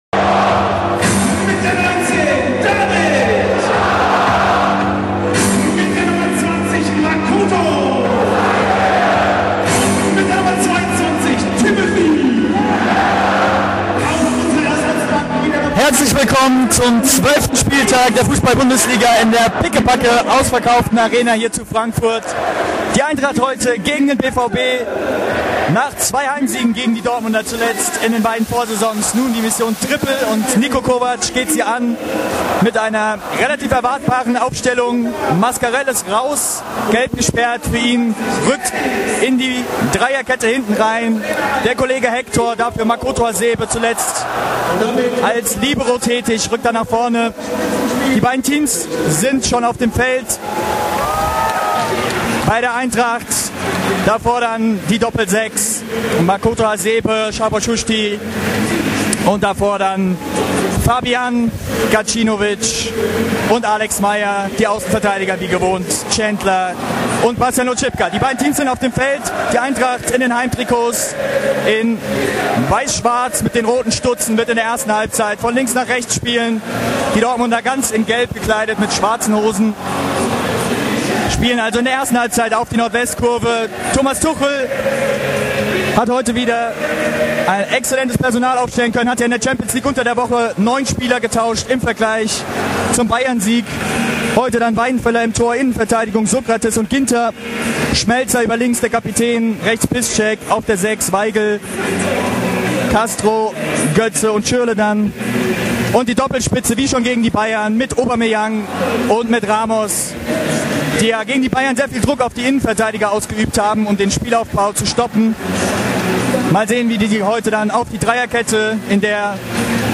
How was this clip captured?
Ort Commerzbank-Arena, Frankfurt